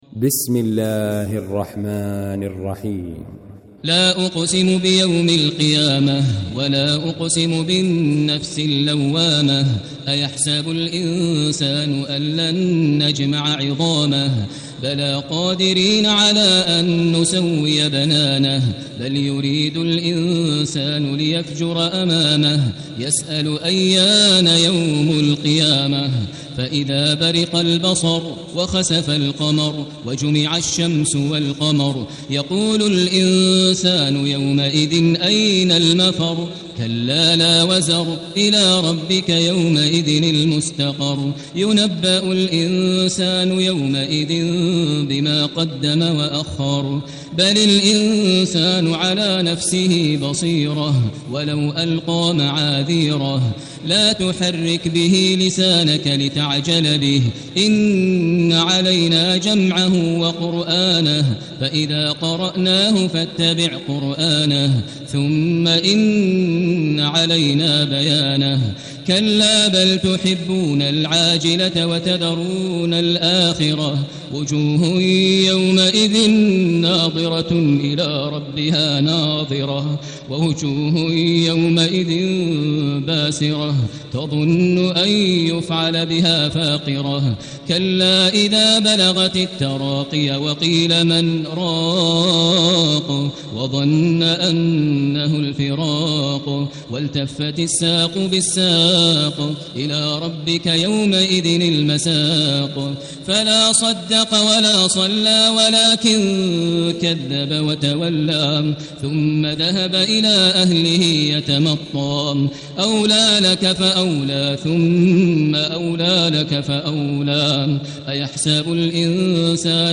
المكان: المسجد الحرام الشيخ: فضيلة الشيخ ماهر المعيقلي فضيلة الشيخ ماهر المعيقلي القيامة